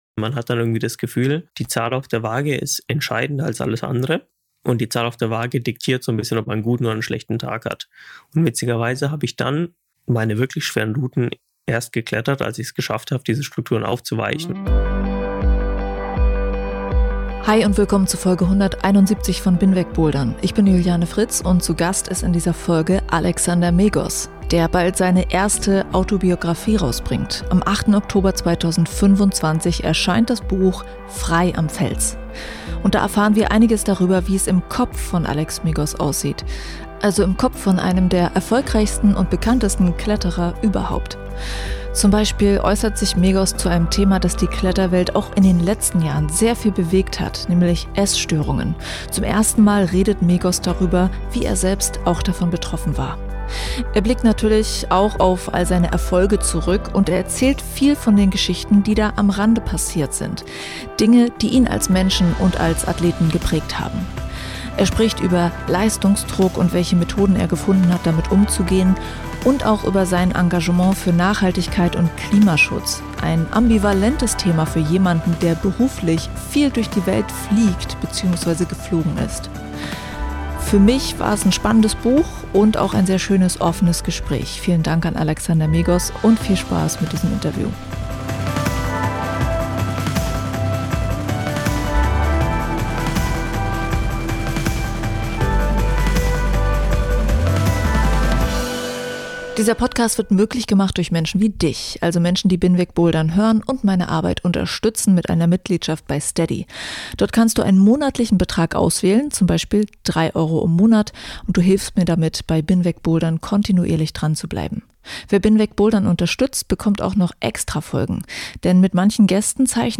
Über die Themen des Buches spricht er jetzt auch im BIN WEG BOULDERN-Interview.